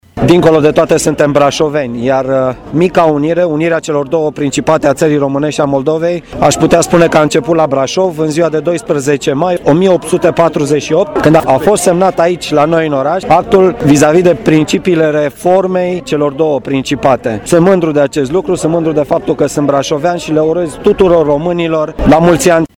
Prefectul Marian Rasaliu a oferit și o mică lecție de istorie: